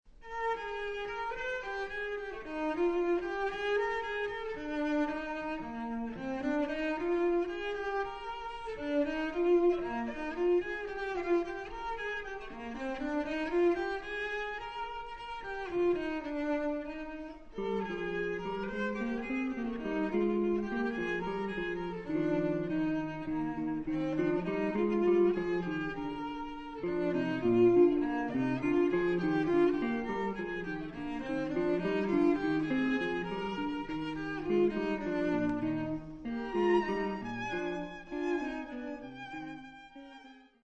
2 violins, electric guitar, cello